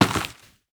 Dirt footsteps 13.wav